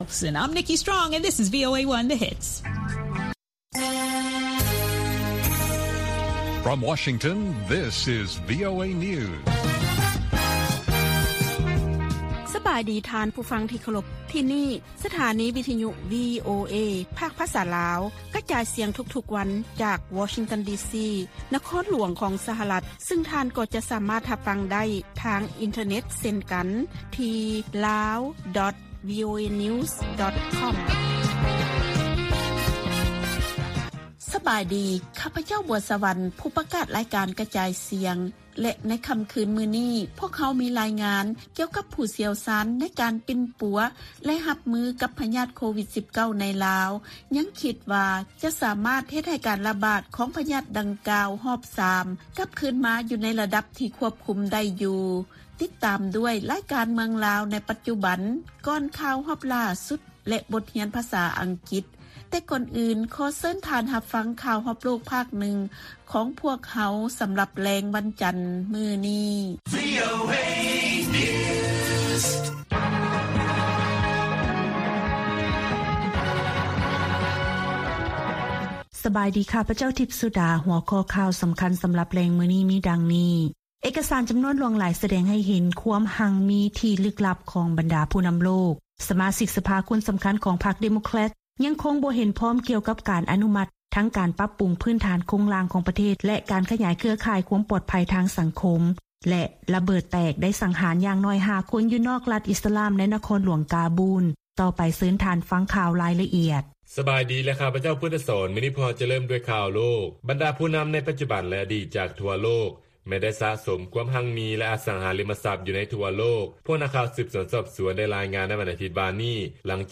ວີໂອເອພາກພາສາລາວ ກະຈາຍສຽງທຸກໆວັນ. ຫົວຂໍ້ຂ່າວສໍາຄັນໃນມື້ນີ້ມີ: 1) ລາວຈະສາມາດຮັບມືກັບ ຄື້ນຟອງການລະບາດຮອບ 3 ຂອງໂຄວິດ-19 ໄດ້ຢູ່ ອີງຕາມຫົວໜ້າທີມຄຸ້ມຄອງກໍລະນີໂຄວິດ-19.